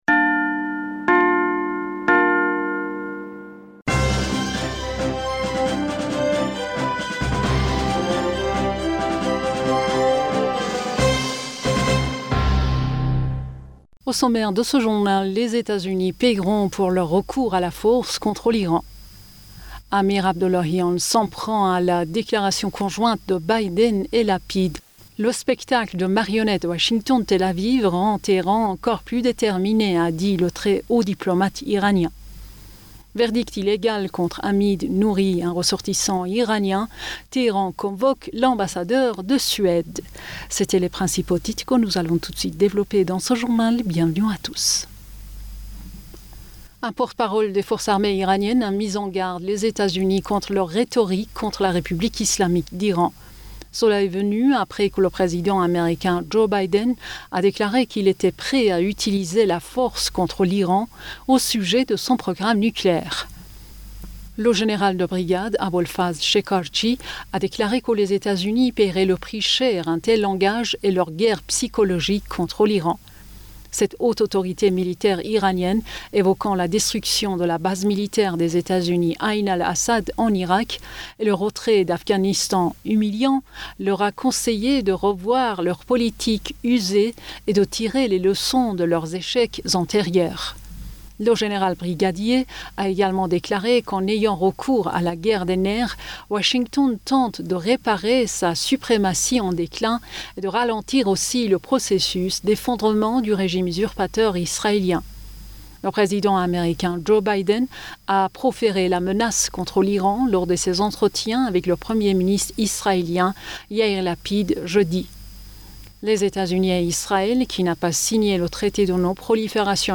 Bulletin d'information Du 15 Julliet